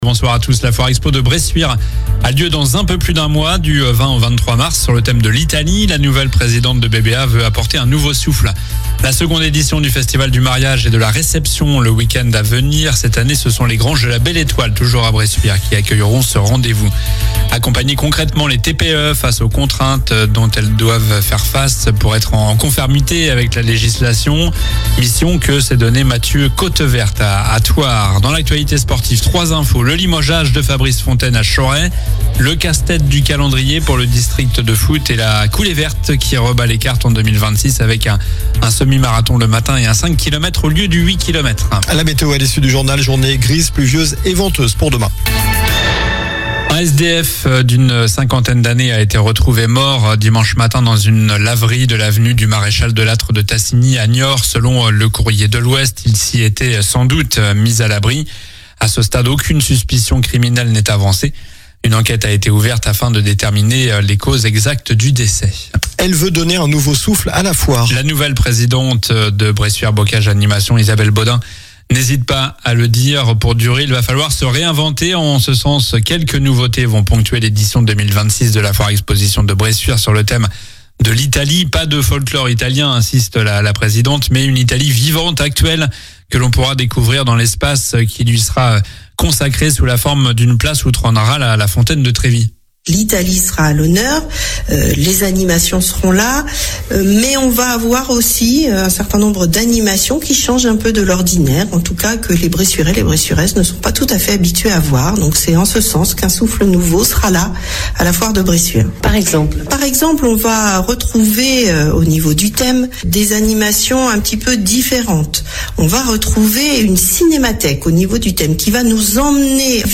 Journal du mardi 17 février (soir)